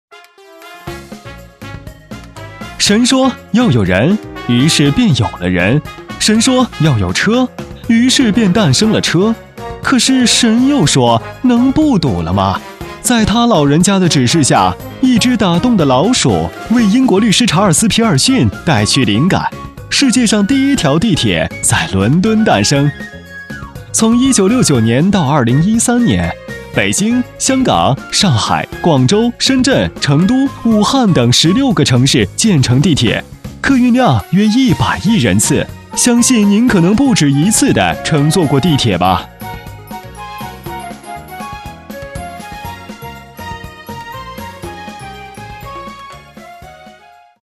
21 男国190_宣传片_病毒_青岛地铁公益诙谐 男国190
男国190_宣传片_病毒_青岛地铁公益诙谐.mp3